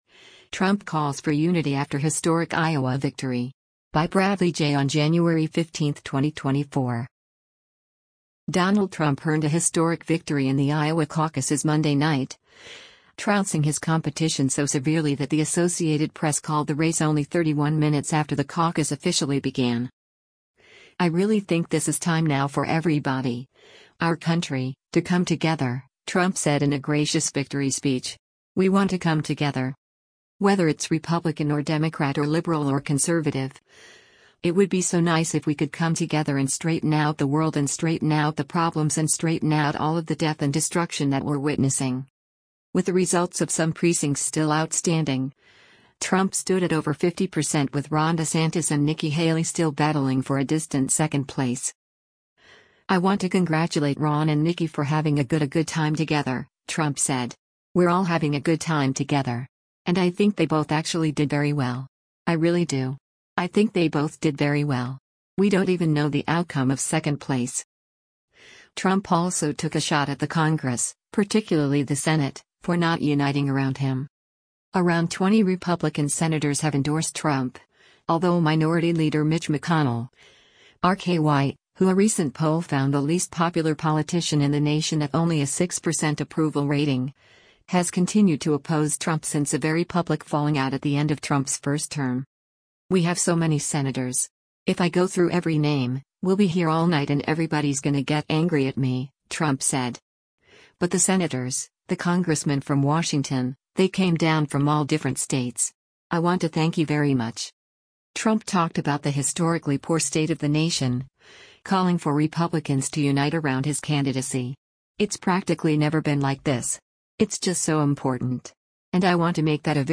Republican presidential candidate former President Donald Trump speaks at a caucus night p